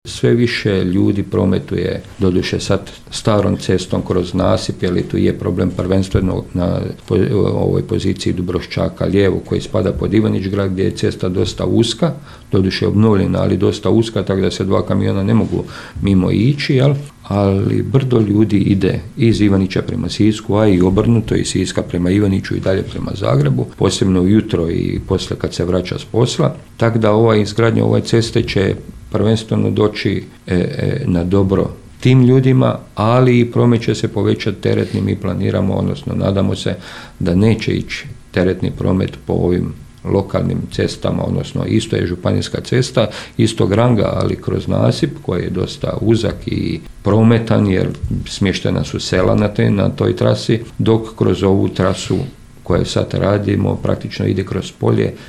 Potvrđuje to načelnik Stjepan Ivoš